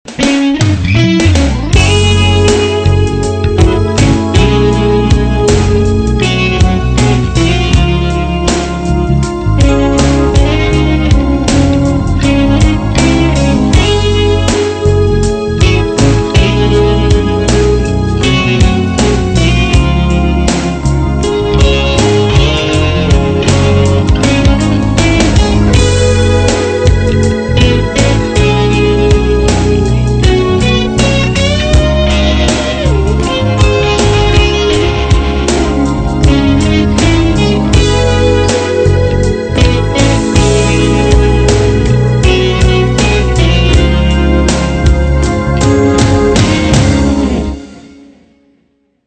Major 渋め
ノスタルジックな想いを込めた曲